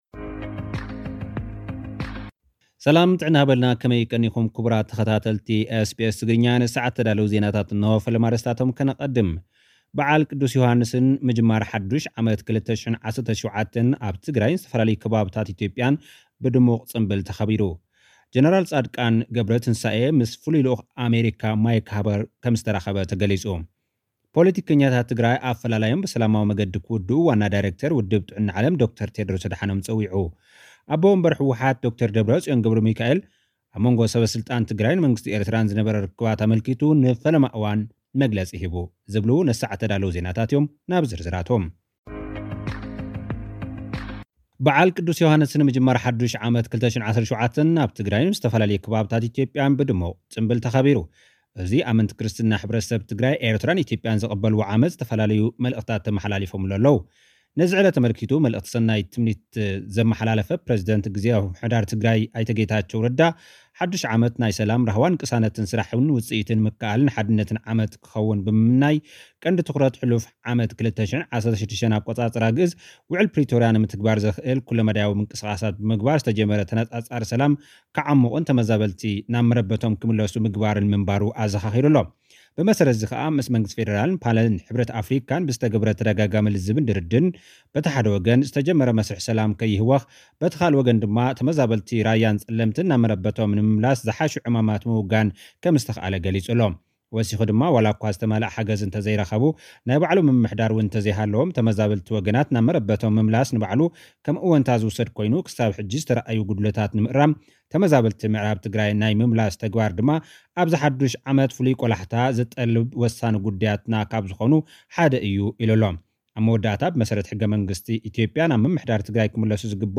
ፖለቲከኛታት ትግራይ ኣፈላላዮም ብሰላማዊ መገዲ ክውድኡ ዋና ዳይሪክቶር ውድብ ጥዕና ዓለም ዶ/ር ቴድሮስ ኣድሓኖም ጸዊዑ። (ጸብጻብ)